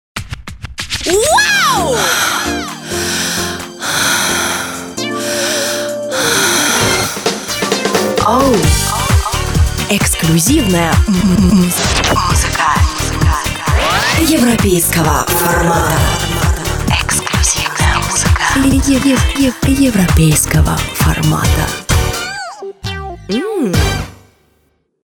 Джингл